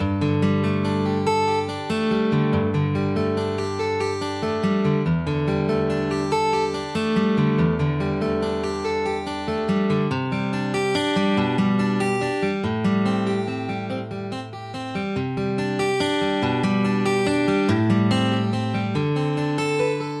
描述：原声吉他在12/8拍的vstkick上。
标签： 原声 C大调 吉他 twelve_eight
声道立体声